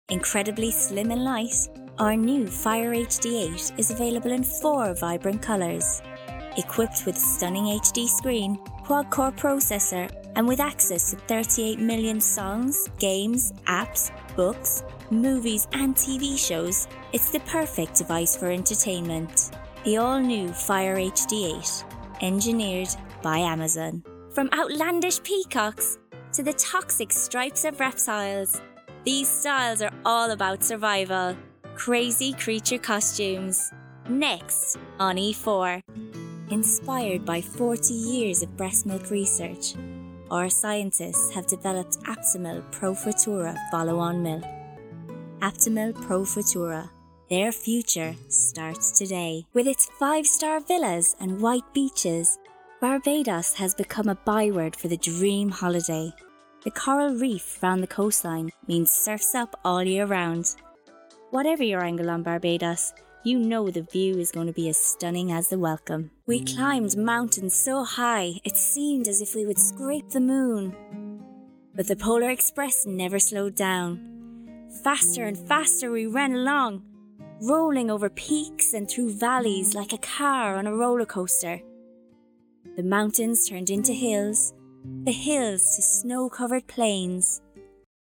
Showreel
Female / 20s / English / Southern Irish Showreel http